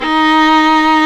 Index of /90_sSampleCDs/Roland - String Master Series/STR_Viola Solo/STR_Vla1 % marc